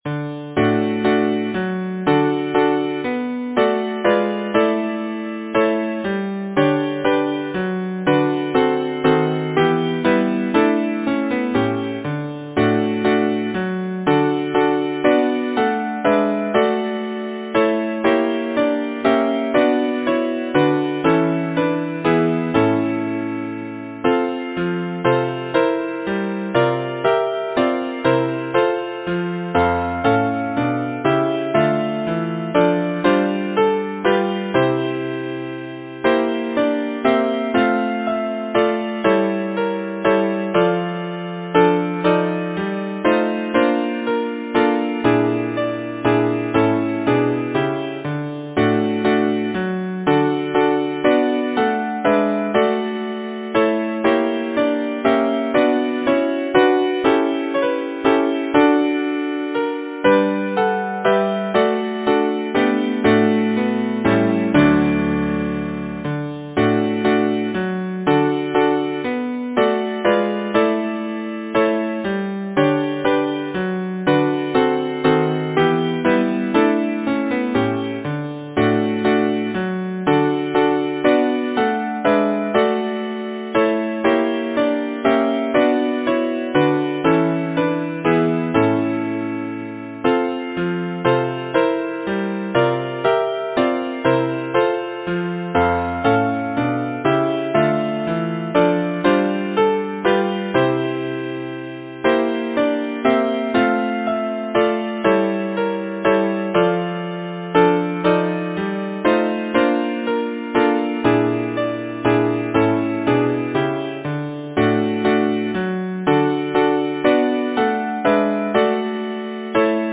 Title: Tell me, Flora Composer: Ciro Pinsuti Lyricist: M. Deigh Number of voices: 4vv Voicing: SATB Genre: Secular, Partsong
Language: English Instruments: A cappella